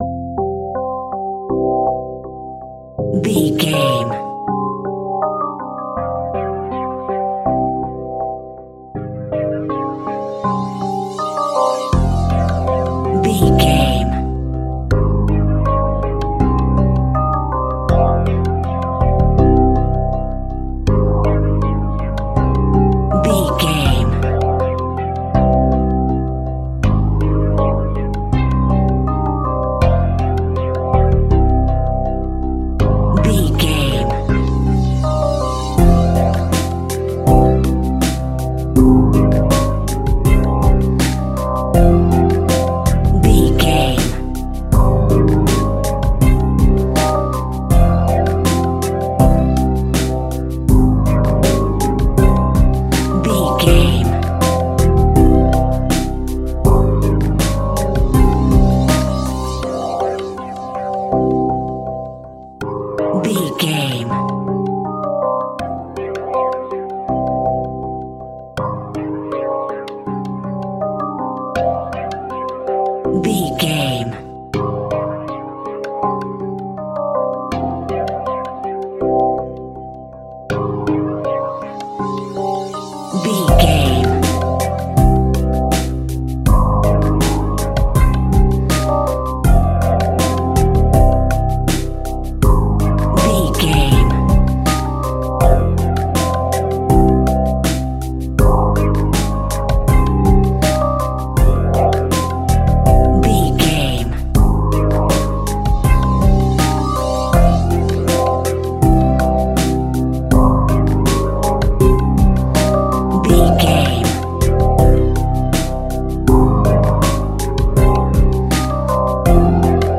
Classic Instrumental Rap Music.
Aeolian/Minor
Slow
hip hop
chilled
laid back
hip hop drums
hip hop synths
piano
hip hop pads